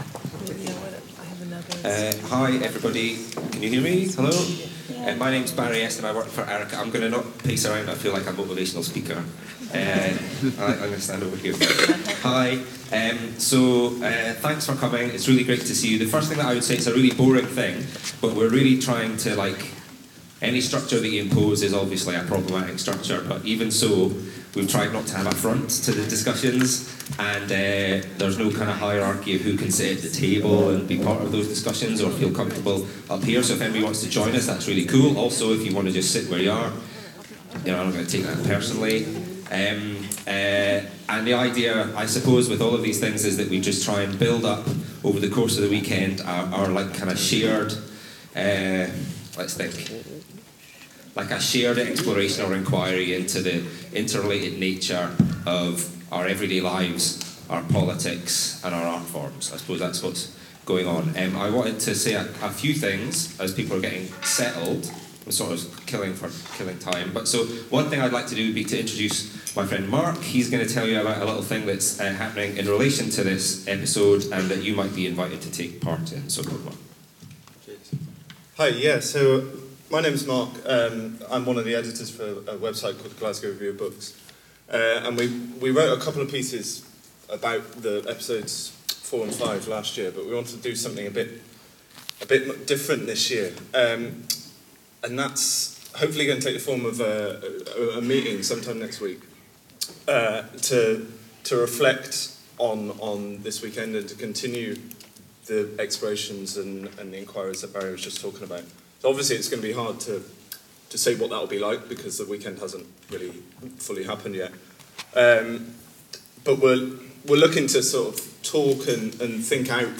An open conversation hosted by Saidiya Hartman and Fred Moten around ‘fugitivity’ and ‘waywardness’ and what it means to be in flight, excessive or ungovernable.